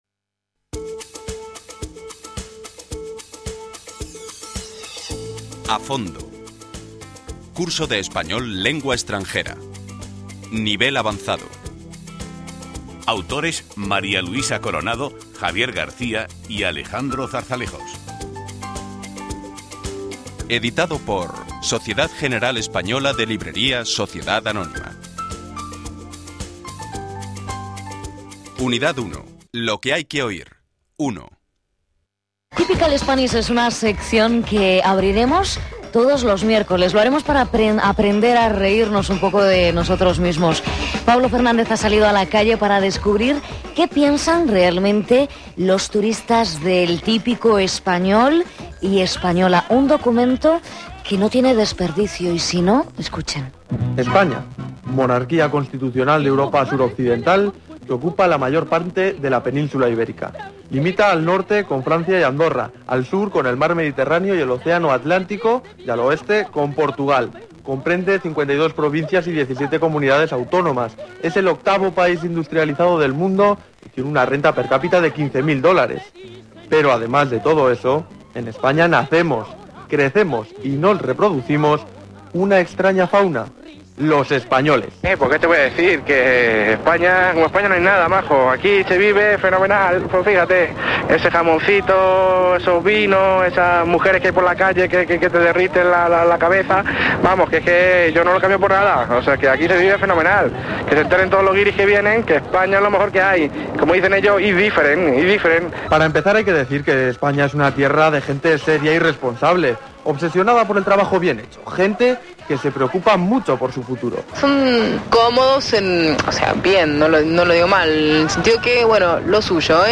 Muchos de estos documentos de audio son materiales auténticos procedentes de noticias difundidas en la radio y la televisión.